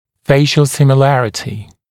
[‘feɪʃ(ə)l ˌsɪmɪ’lærətɪ][‘фэйш(э)л ˌсими’лэрити]похожие черты лица, внешнее сходство (лица)